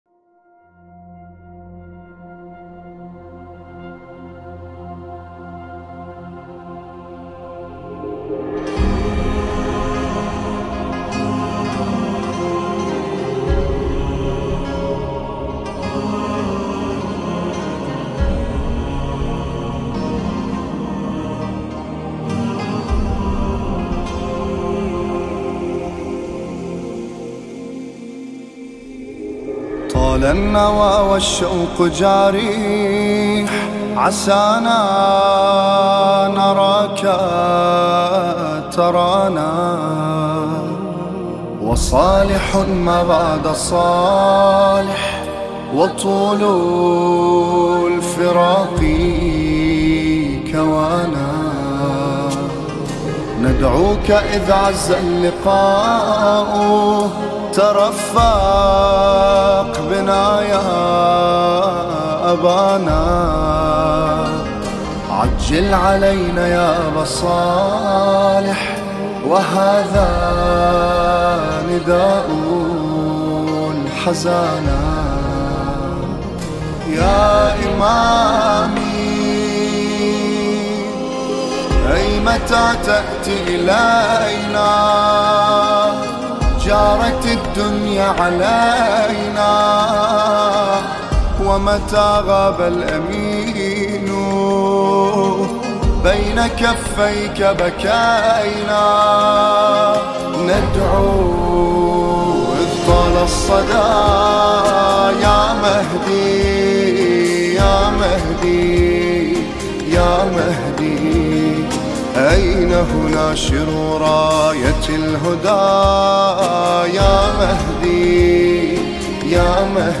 نماهنگ عربی دلنشین و احساسی